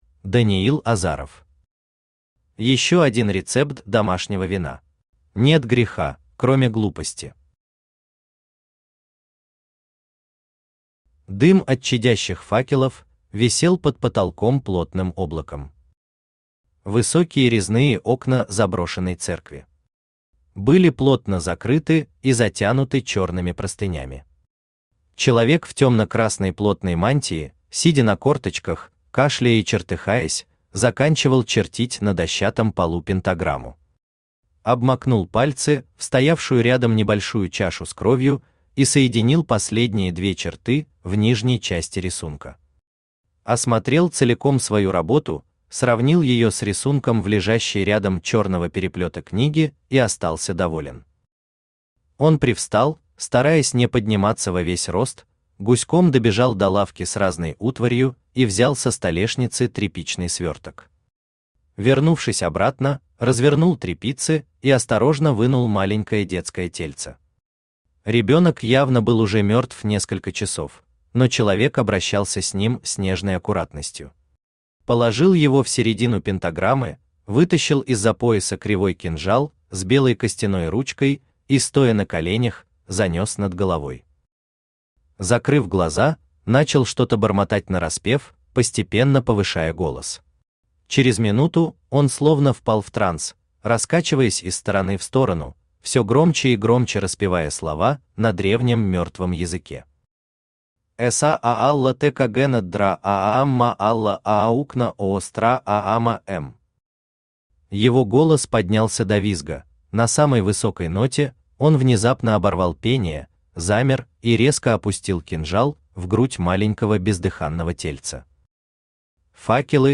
Аудиокнига Еще один рецепт домашнего вина | Библиотека аудиокниг
Aудиокнига Еще один рецепт домашнего вина Автор Даниил Азаров Читает аудиокнигу Авточтец ЛитРес.